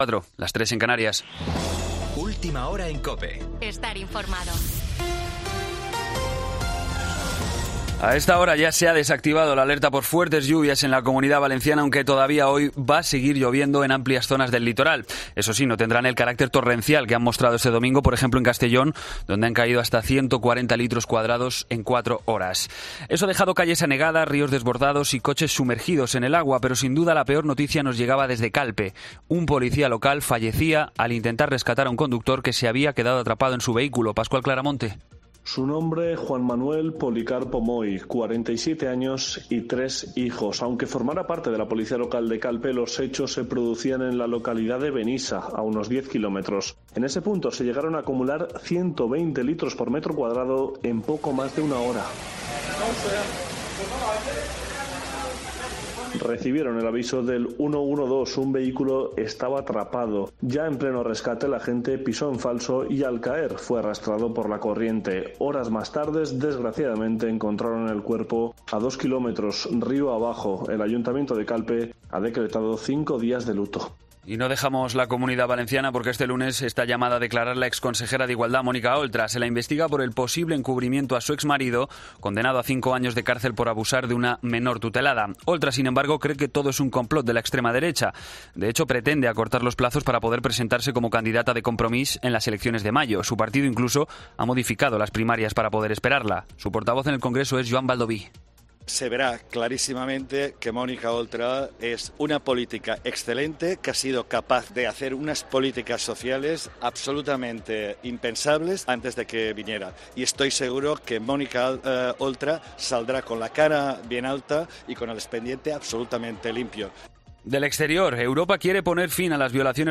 Boletín de noticias COPE del 19 de septiembre a las 04:00 hora
AUDIO: Actualización de noticias Herrera en COPE